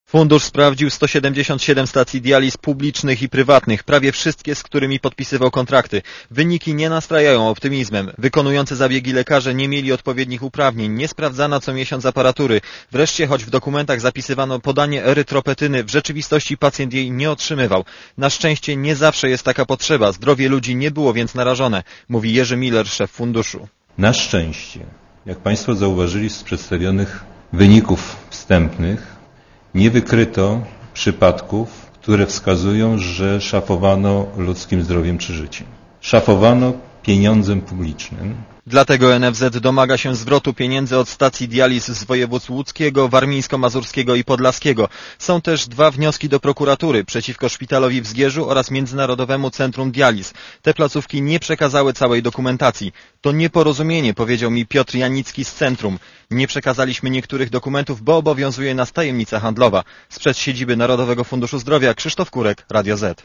reportera Radia ZET*